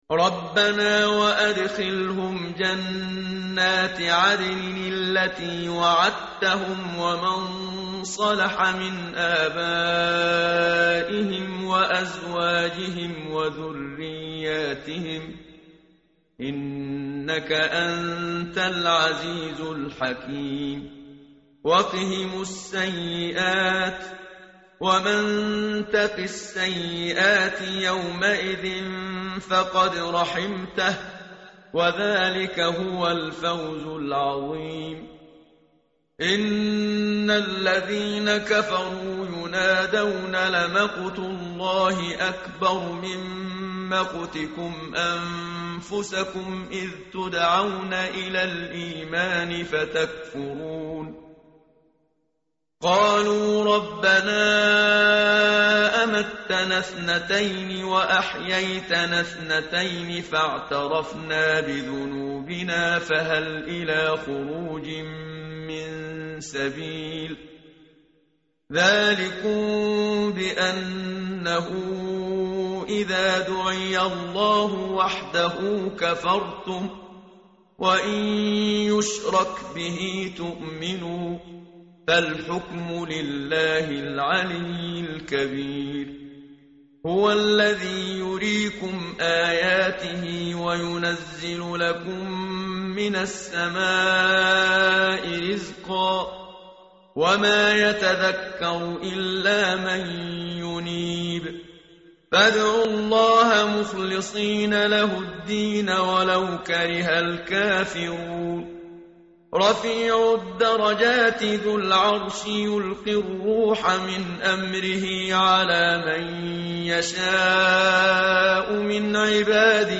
متن قرآن همراه باتلاوت قرآن و ترجمه
tartil_menshavi_page_468.mp3